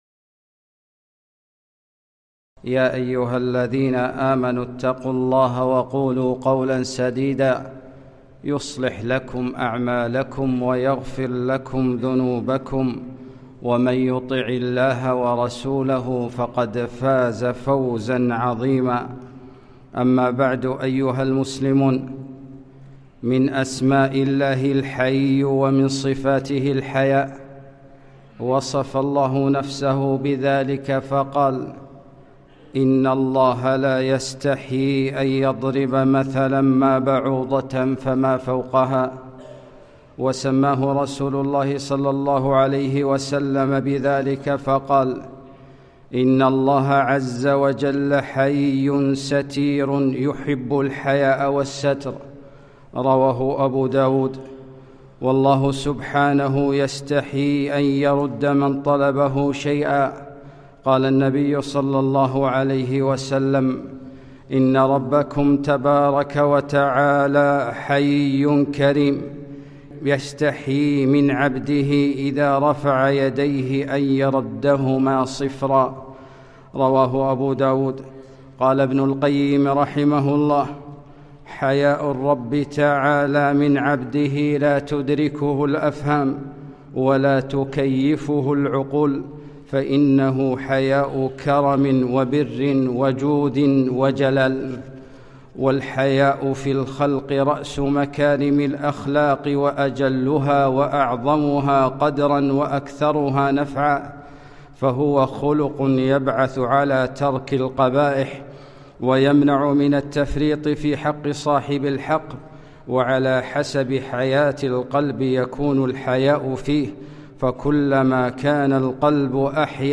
خطبة - لا يأتي إلا بخير